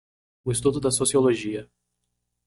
Read more Noun Verb estudar to study Read more Frequency B2 Pronounced as (IPA) /isˈtu.du/ Etymology From Old Galician-Portuguese estudo, estudio, studo, from Latin studium.